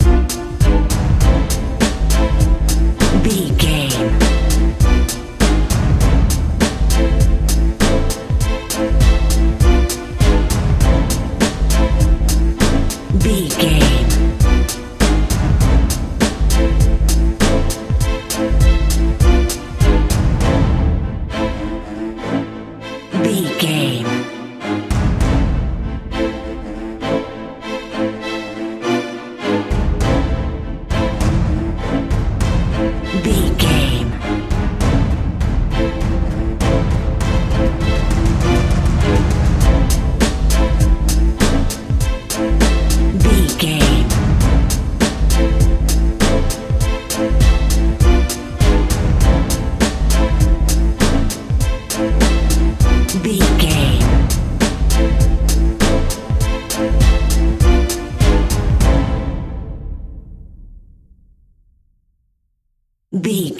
Aeolian/Minor
bass guitar
synthesiser
strings
cello
double bass
drum machine
percussion
hip hop
soul
Funk
neo soul
acid jazz
confident
energetic
bouncy
funky